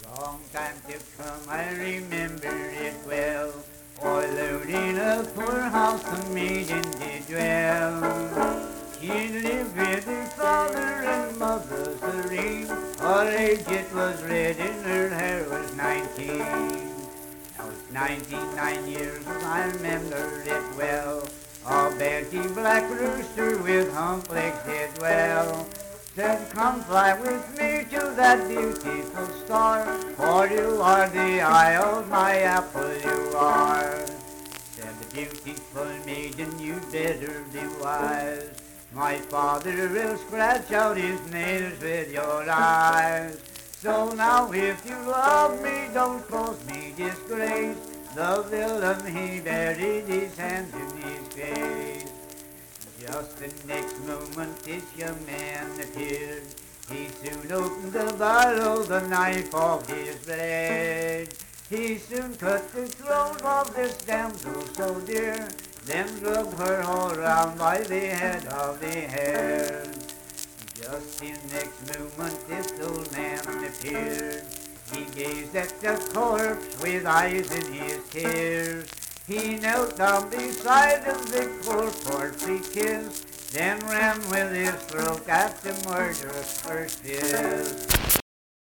Banjo accompanied vocal music performance
Banjo, Voice (sung)